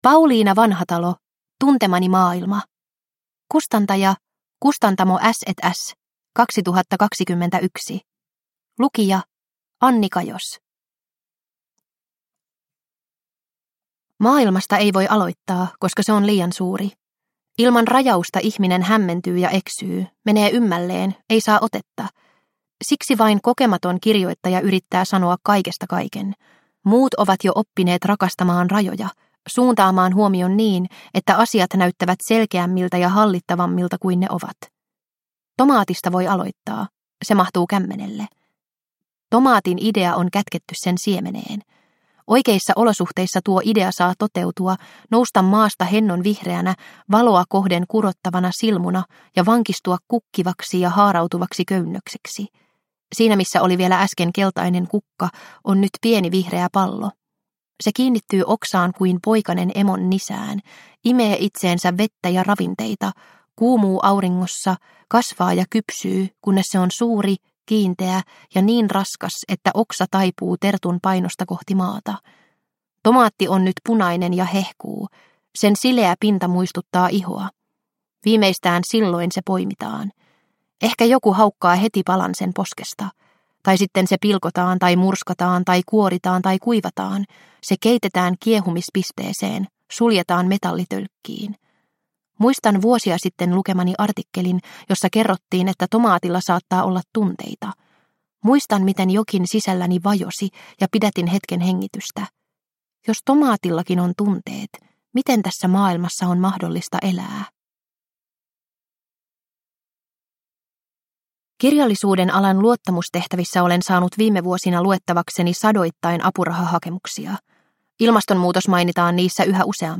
Tuntemani maailma – Ljudbok – Laddas ner